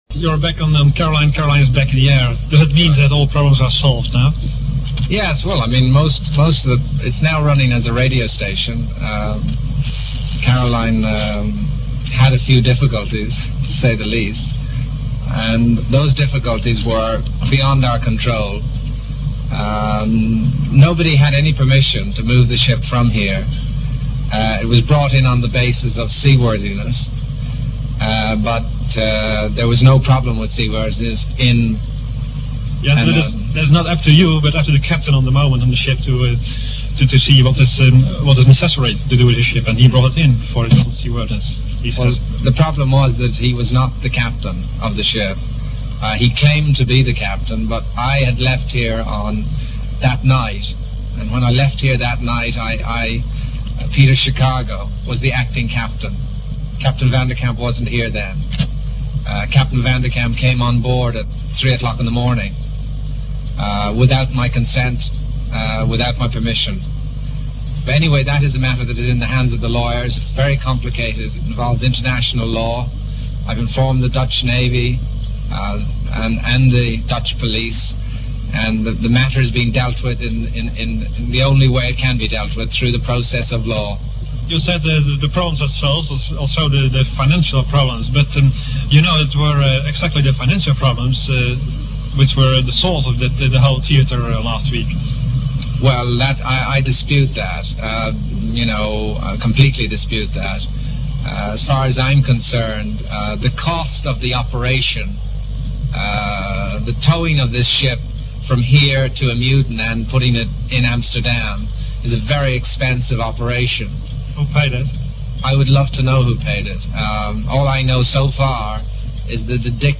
click to hear audio Radio Caroline founder, Ronan O'Rahilly, being interviewed by a Dutch reporter about the mutiny.